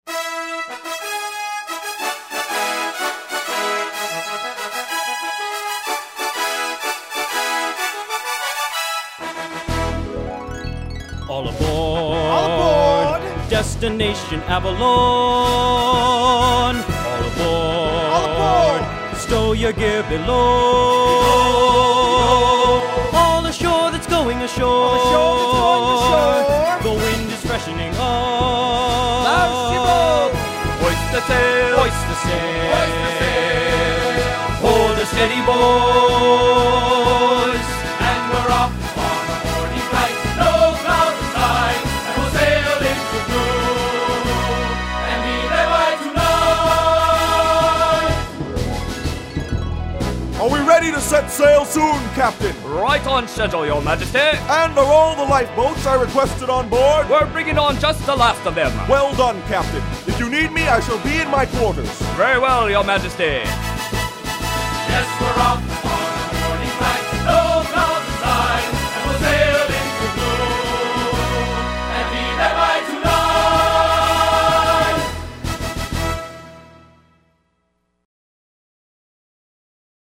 Genre: Soundtrack recordings of popular stage musicals.